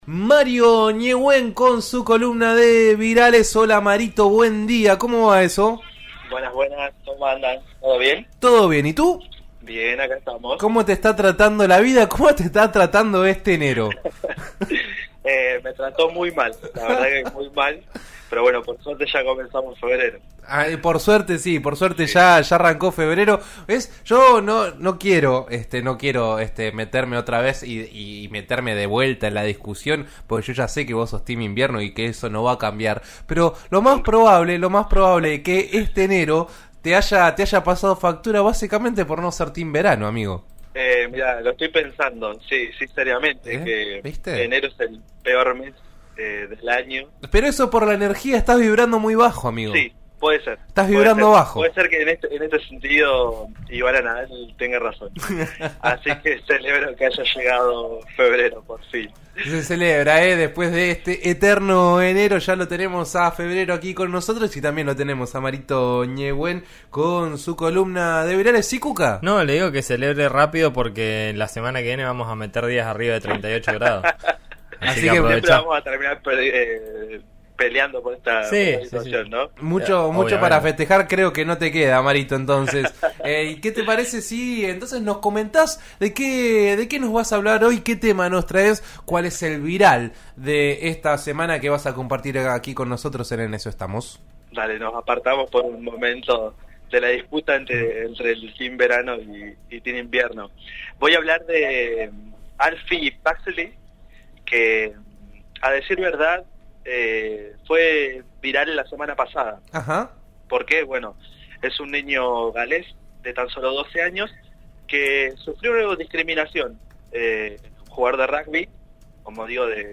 El bullying en las redes sociales no afloja: la columna de virales de RN Radio